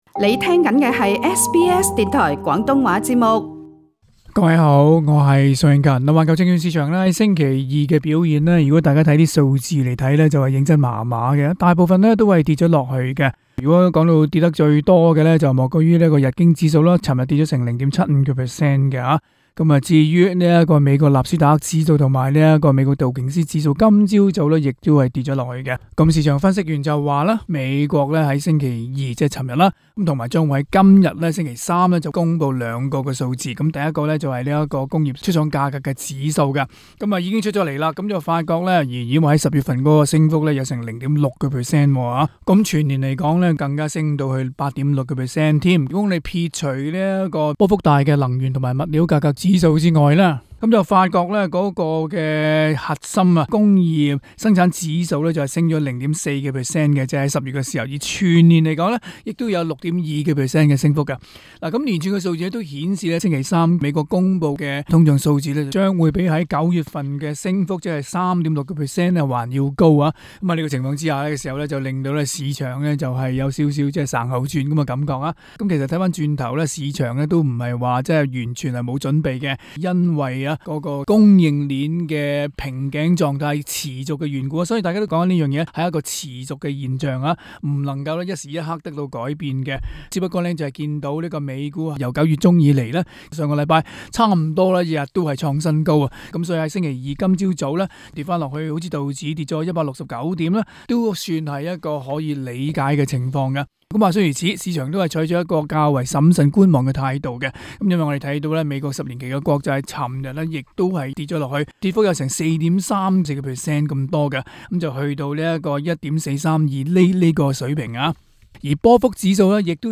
詳情請收聽今日的訪問節目。